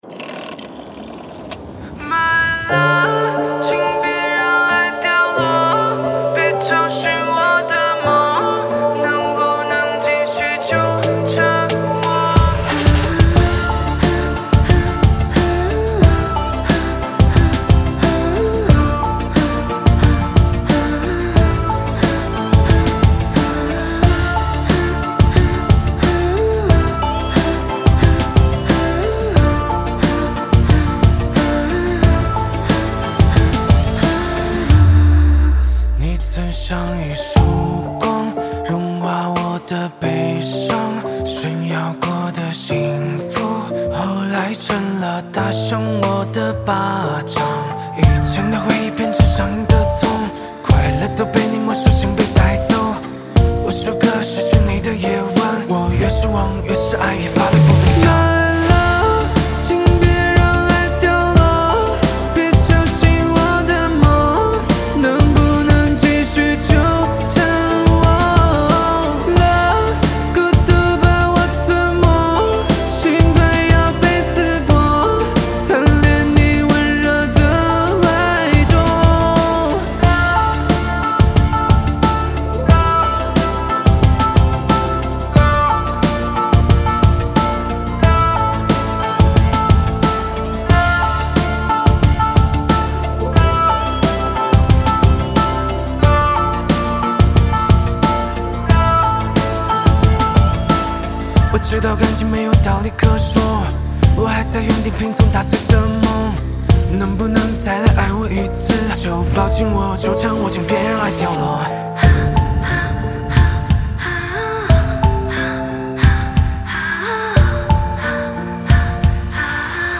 谱内音轨：架子鼓